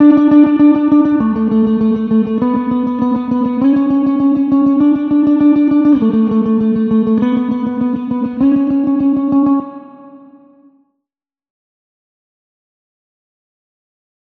Federhall
Soundbeispiel Gitarre
Federhall zeichnet sich durch deutlich wahrnehmbare, diskrete Reflexionen aus.
gitarreampspring.wav